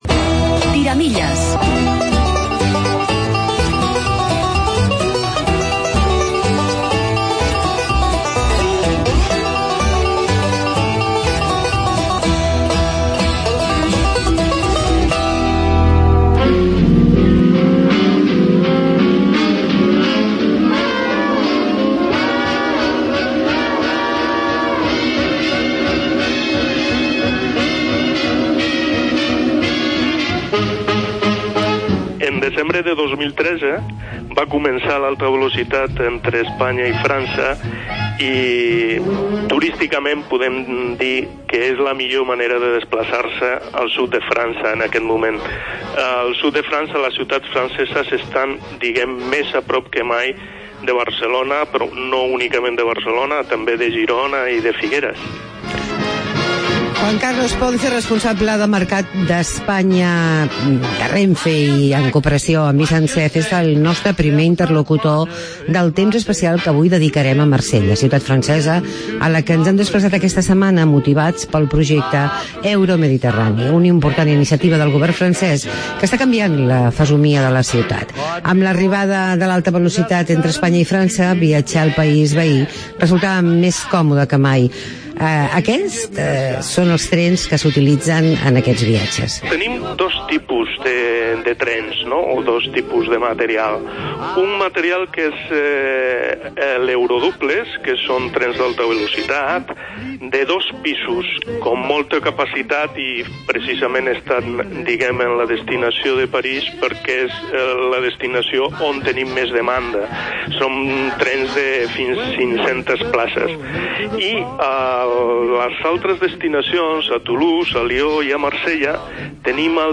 Reportaje Viaje a Marsella